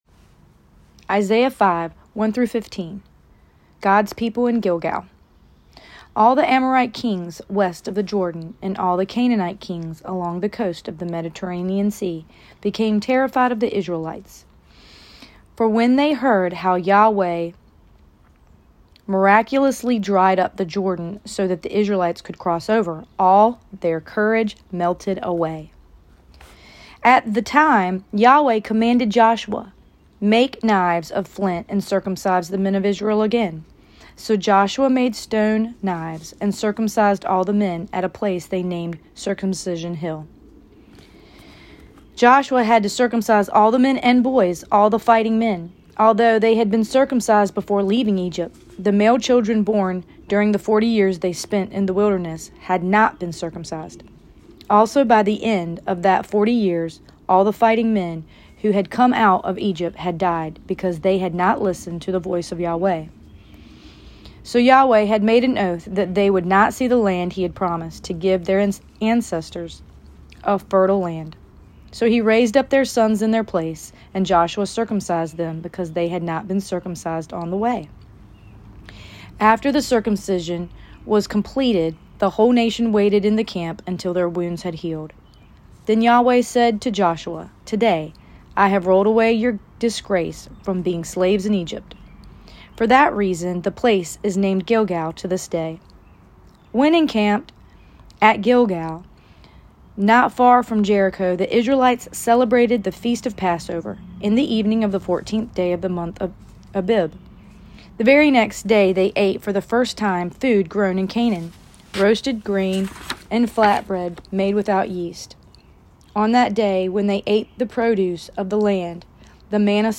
Here is a recording of Joshua 5:1-15 read by me: